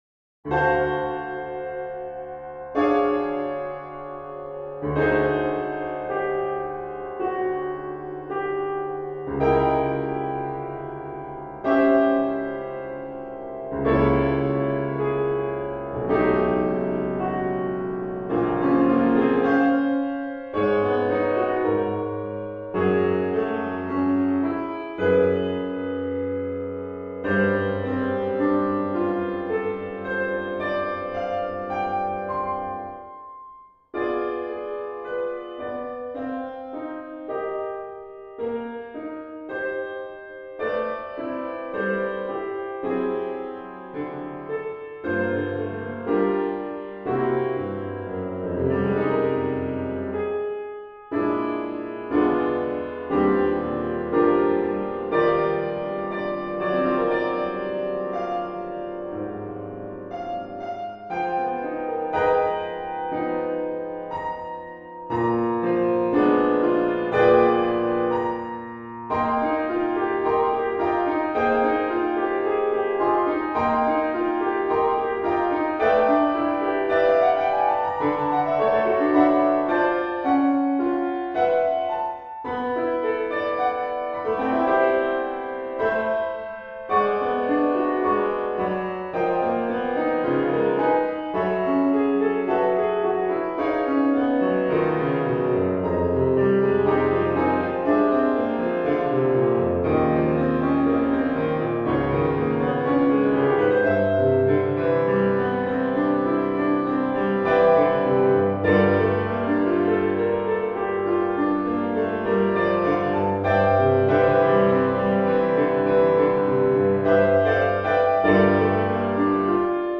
Genre: Solo Piano
Solo Piano